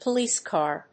アクセントpolíce càr